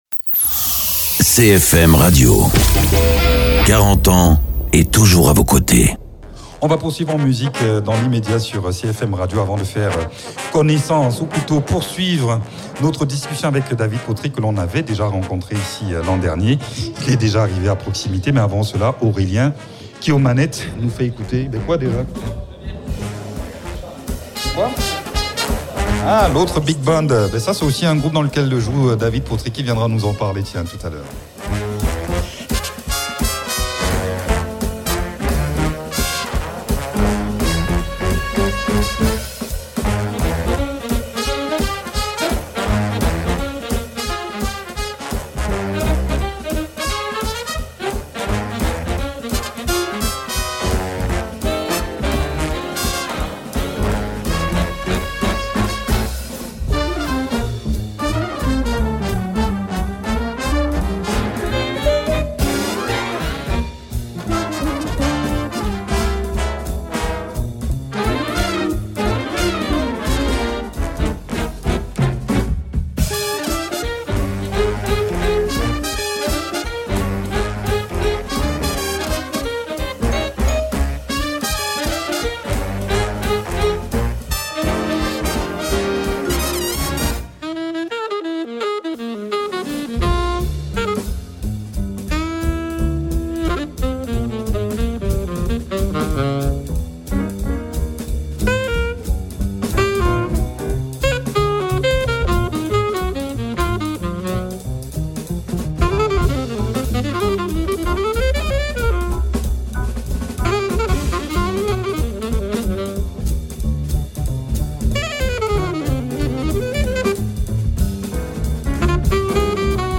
saxophoniste et prof de jazz.